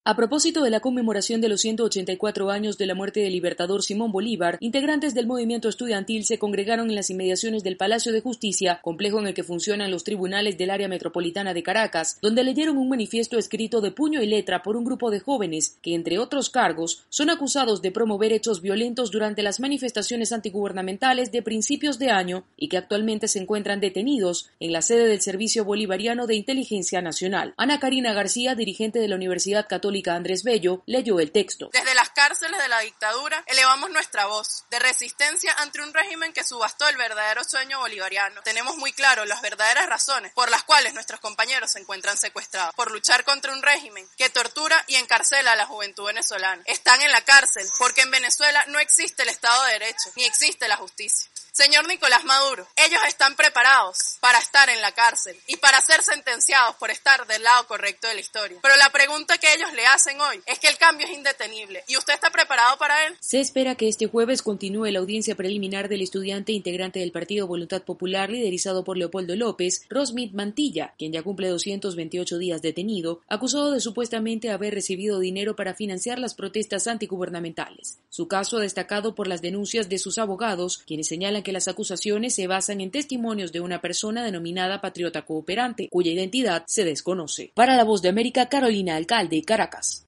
En Venezuela miembros del movimiento estudiantil aseguran que en 2015 continuarán la lucha hasta lograr justicia para presos y perseguidos políticos. Desde Caracas informa